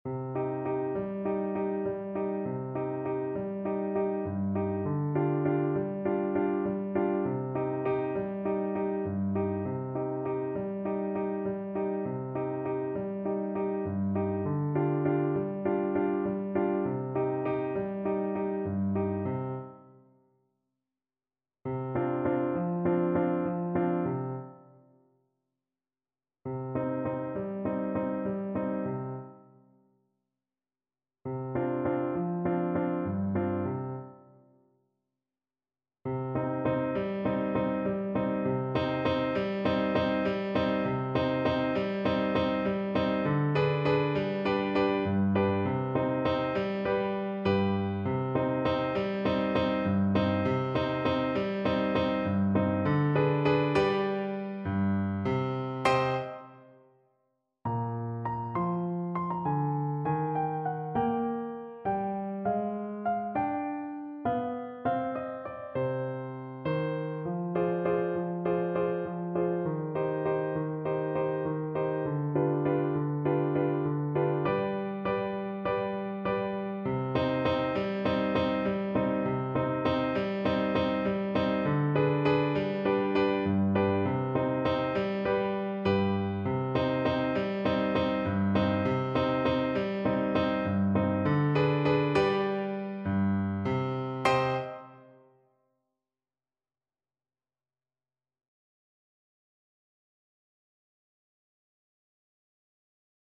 A sultry and atmospheric piece.
8/8 (View more 8/8 Music)
Moderato
World (View more World Clarinet Music)